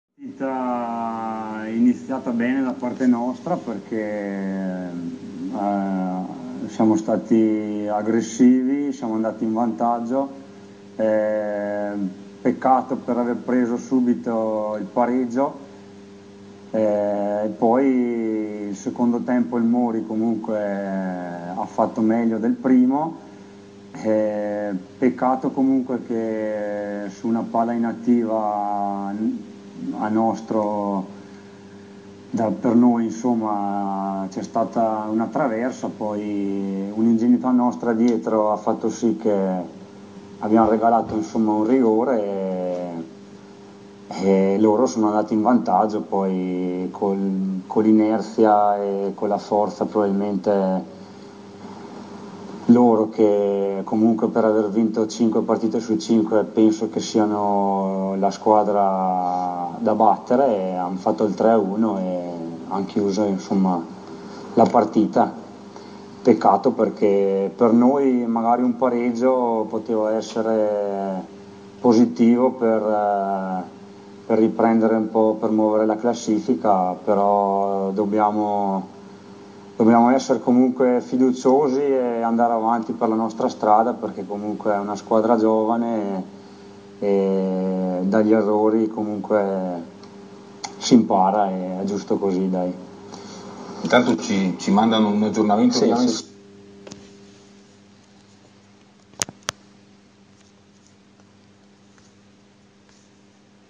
il commento
audio fonte trentinoTv